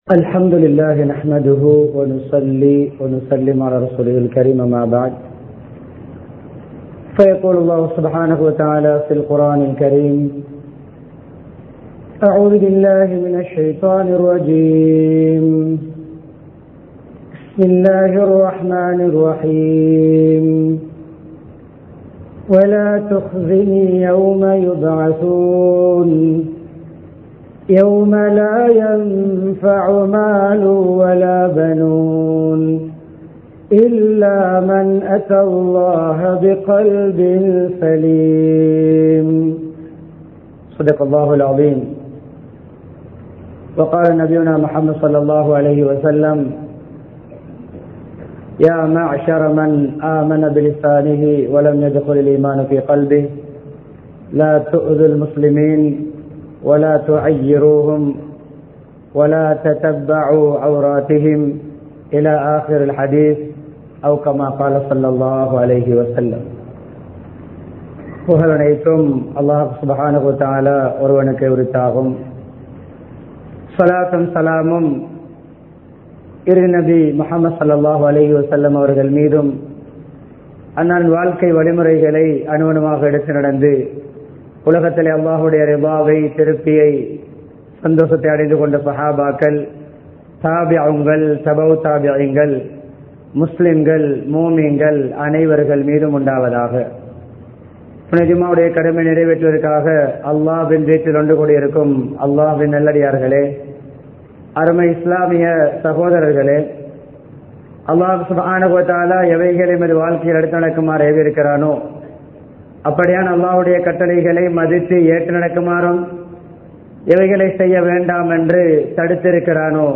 தூய உள்ளம் (Pure Heart) | Audio Bayans | All Ceylon Muslim Youth Community | Addalaichenai
Matale, Gongawela Jumua Masjidh